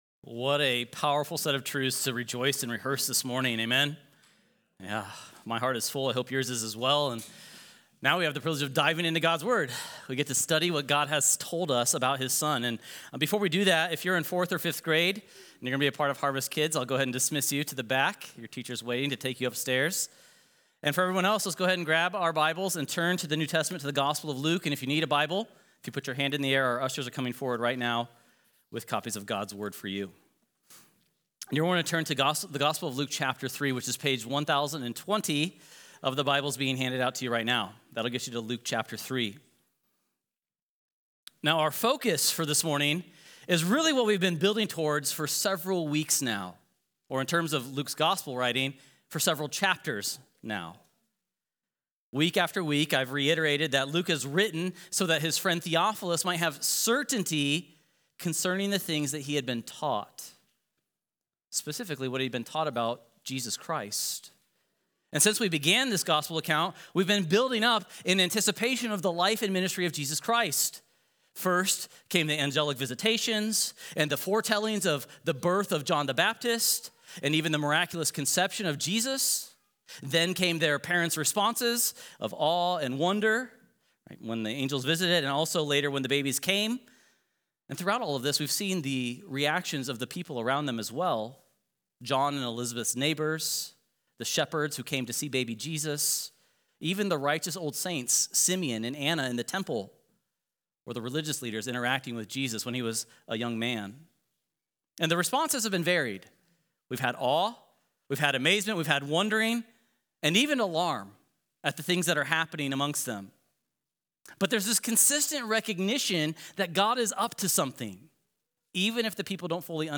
Sermon As we turn our attention now to the study of God’s Word…